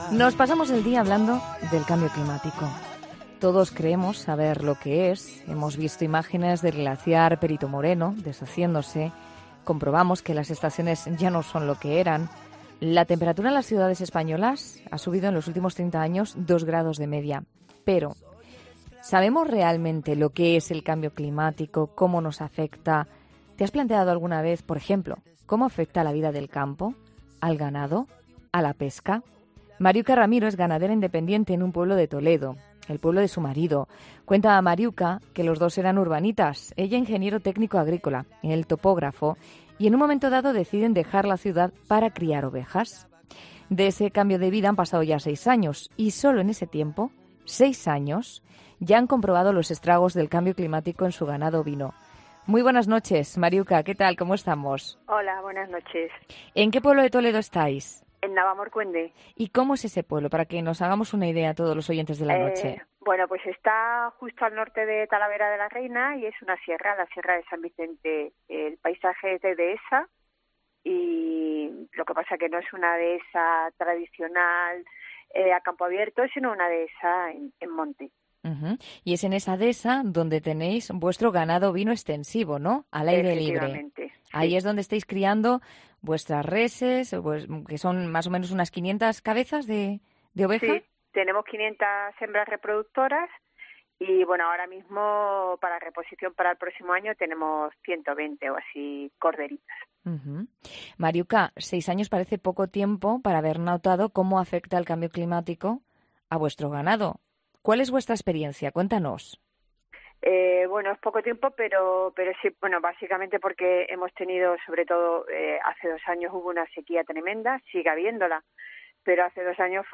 Hablamos con una ganadera, un agricultor y un pescador que nos explican cómo está afectando en nuestro país.